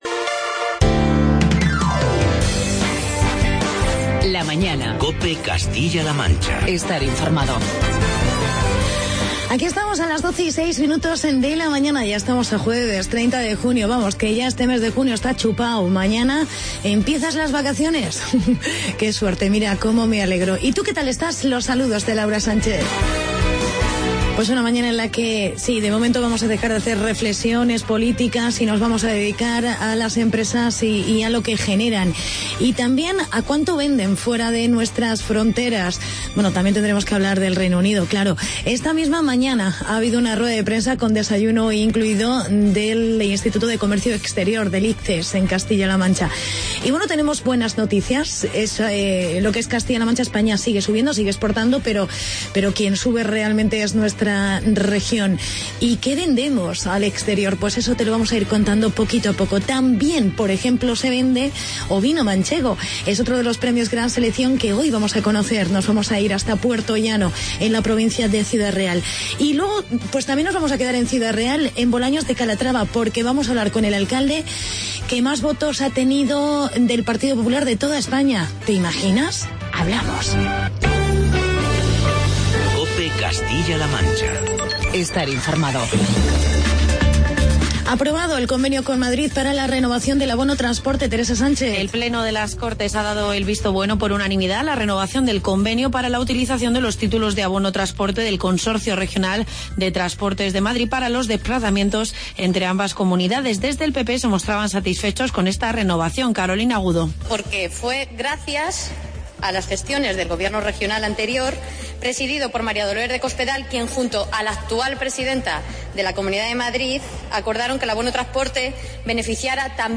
Entrevista a Pedro Morejón, Director territorial de Comercio y del ICEX CLM.